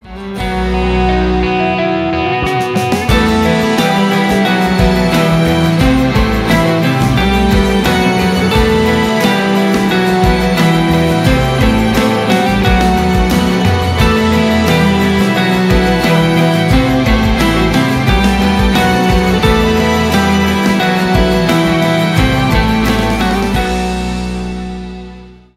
без слов